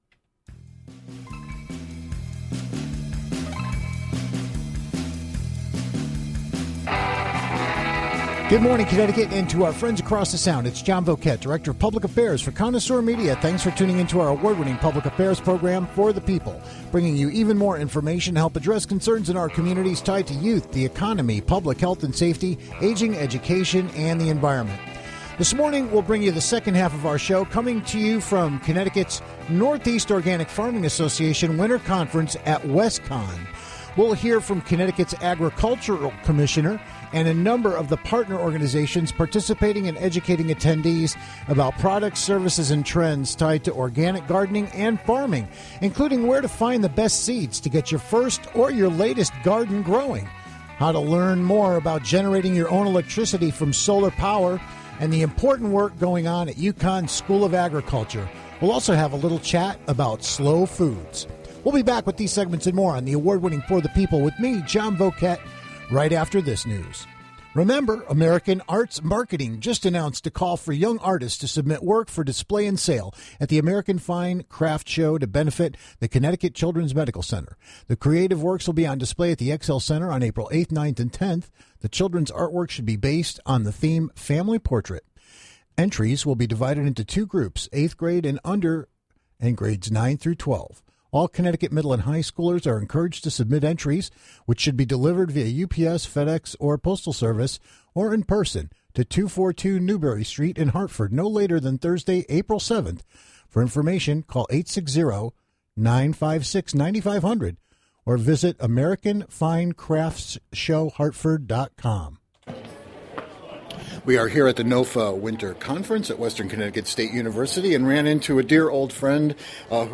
a two-part report from the Northeast Organic Farming Association's recent Conference at WestConn. We'll visit with Connecticut's Agriculture Commissioner, and talk to exhibitors to find the best seeds to get your first or latest garden started, generating your own electricity from solar power, we'll dig into composting with UConn's School of Agriculture and have a leisurely chat about slow foods.